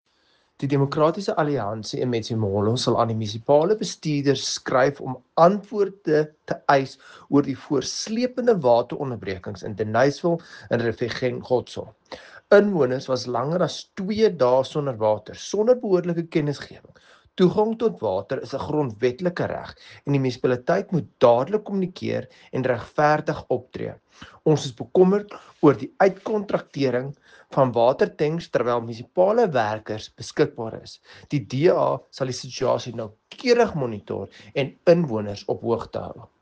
Afrikaans soundbite by Dr Igor Scheurkogel MP.